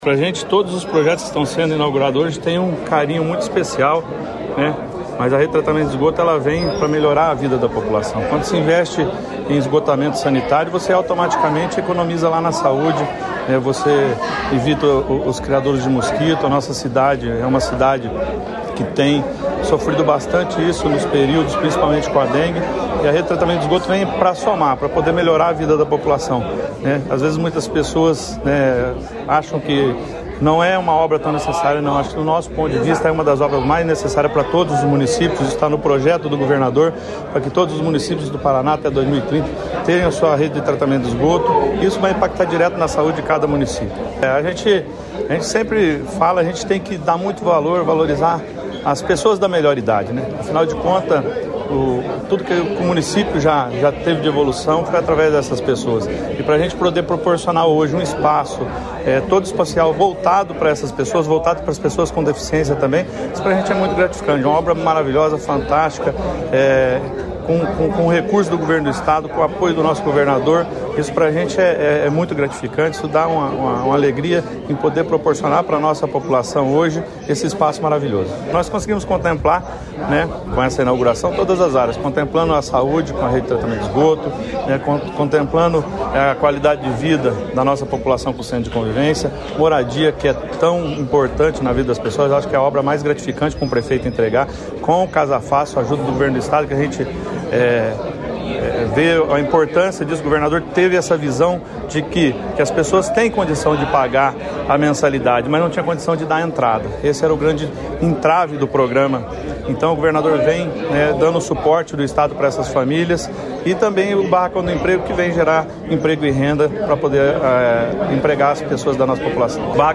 Sonora do prefeito de Leópolis, Alessandro Ribeiro, sobre a entrega de rede de esgoto, centro de convivência e moradias na cidade